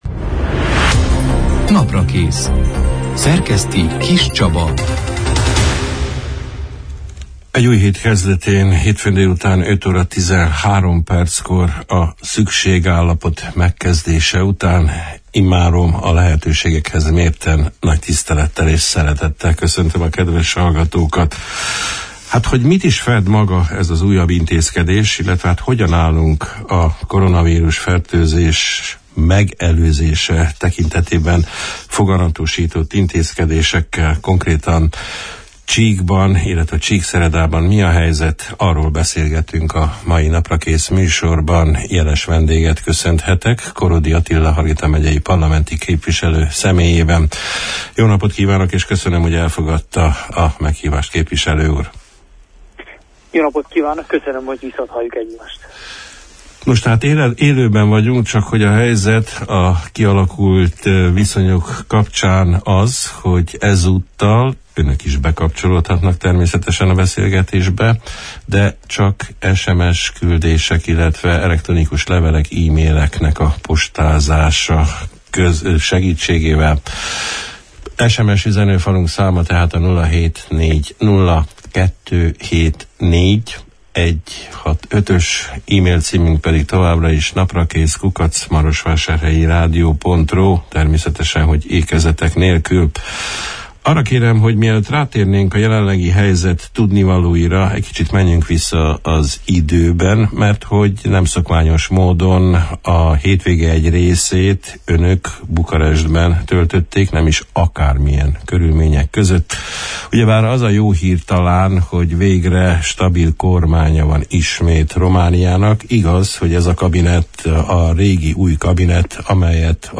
A szükségállapot bevezetésének következményeiről, a betartandó előírásokról, a koronavírus – járvány megfékezése érdekében hozott intézkedésekről, a Hargita megyei konkrét helyzetről beszélgettünk a március 16 – án, hétfőn délután elhangzott Naprakész műsorban, Korodi Attila parlamenti képviselővel.